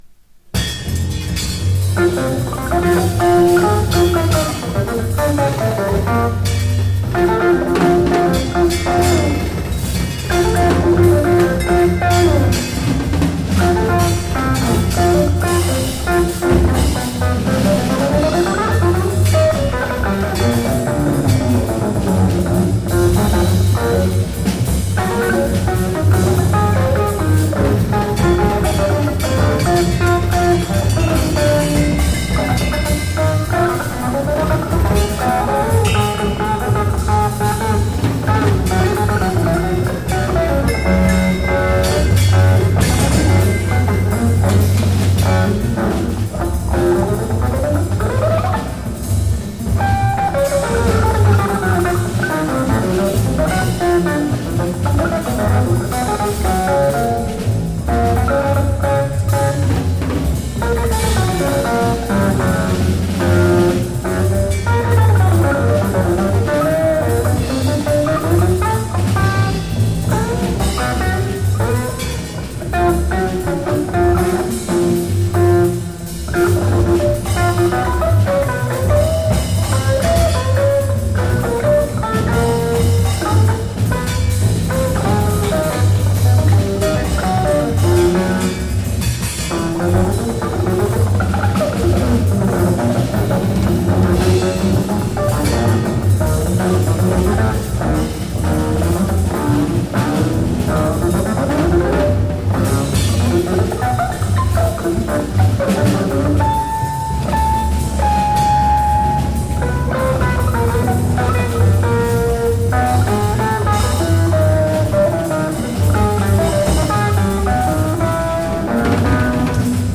強烈な演奏です。